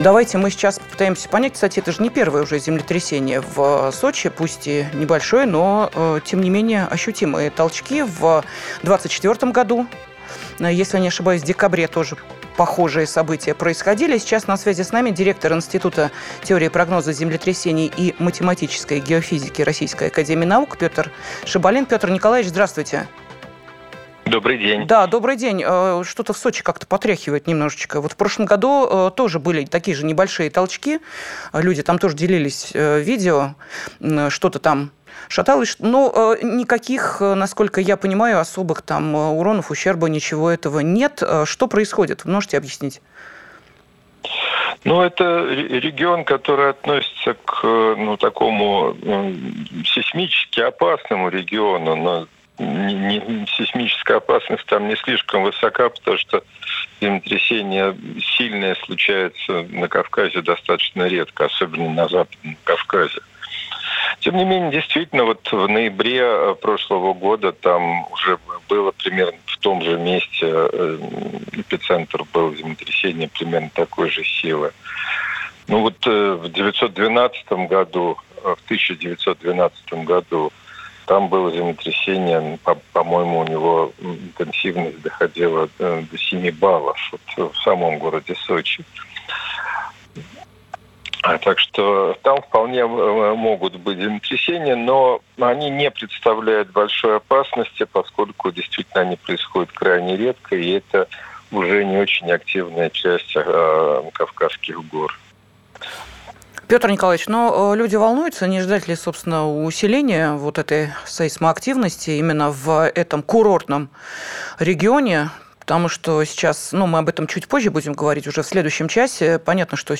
Ознакомиться с отрывком эфира можно ниже: